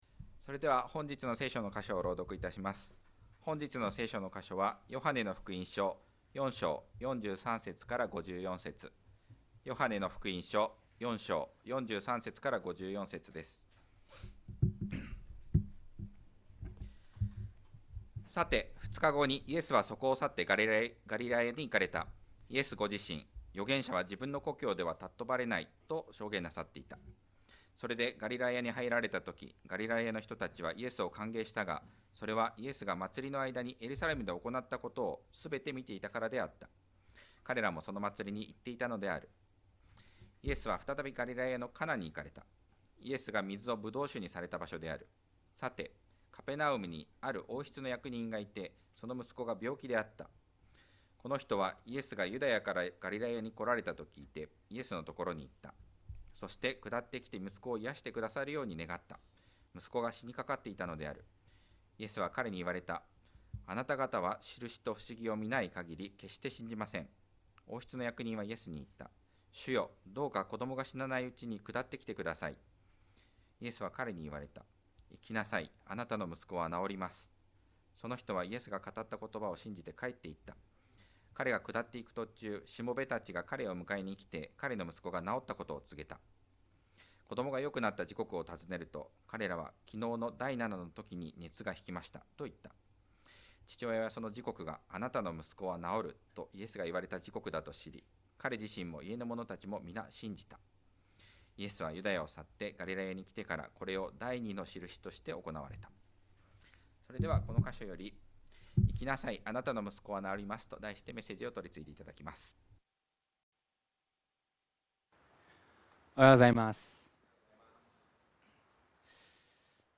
2025年3月16日礼拝 説教 「行きなさい。あなたの息子は治ります」 – 海浜幕張めぐみ教会 – Kaihin Makuhari Grace Church